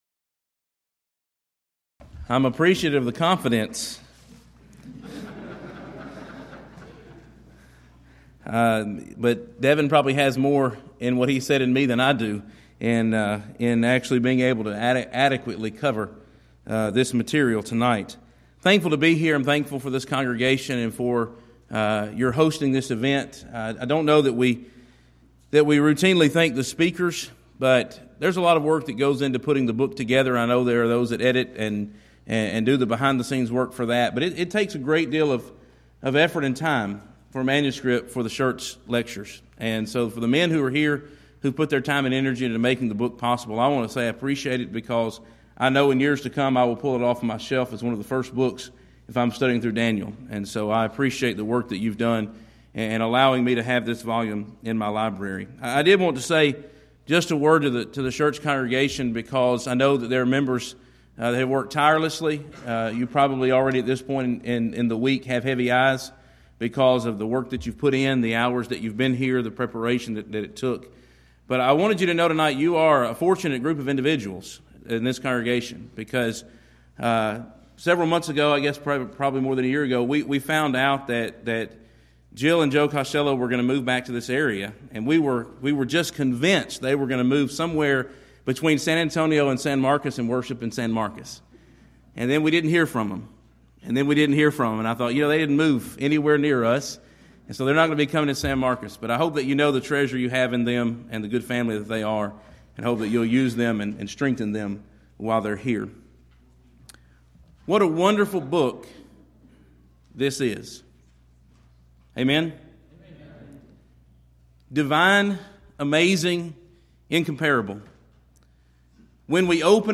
Event: 11th Annual Schertz Lectures